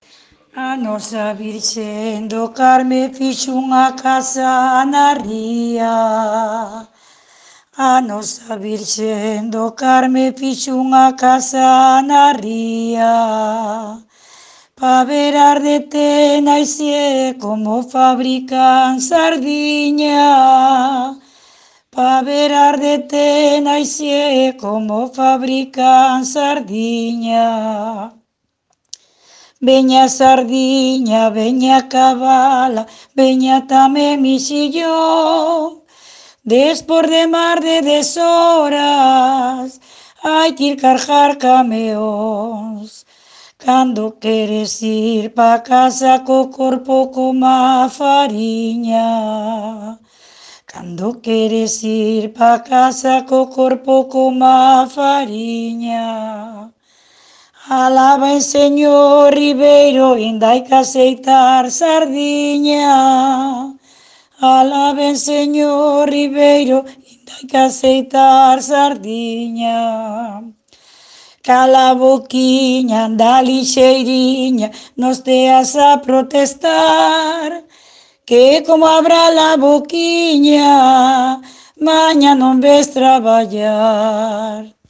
Tipo de rexistro: Musical
LITERATURA E DITOS POPULARES > Cantos narrativos
Instrumentación: Voz
Instrumentos: Voz feminina
Gravación do canto dunha traballadora da fábrica conserveira Tenaisié (Thenaisie Provoté) do Grove.
A melodía que utiliza é a da Rianxeira.